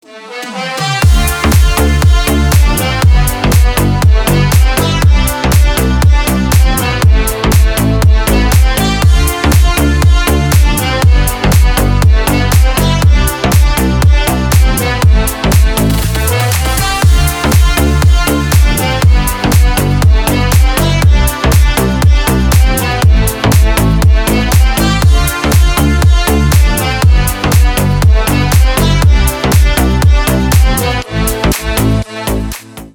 Танцевальные
клубные # без слов